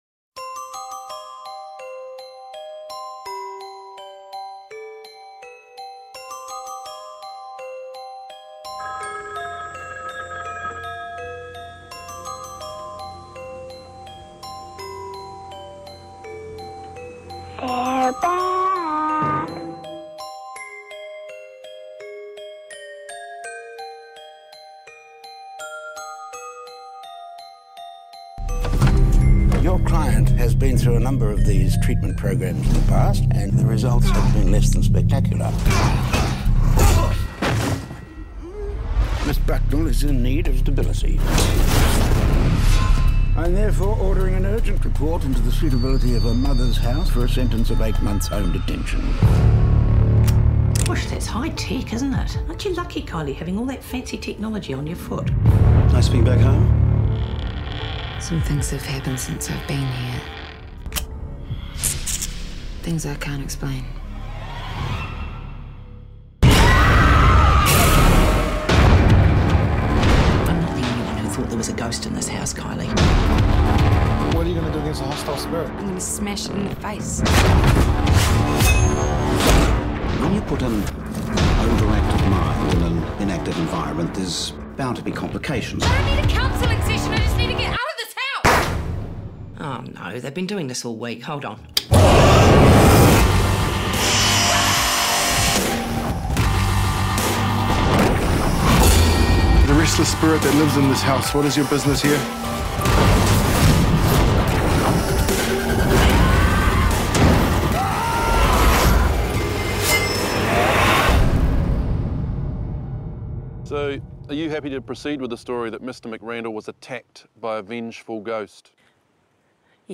Unfortunately, technical difficulties affected…